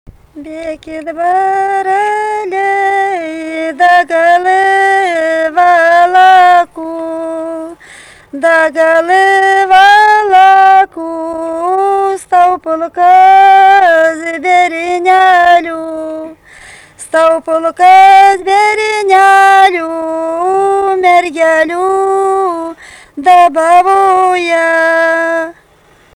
vokalinis
3 balsai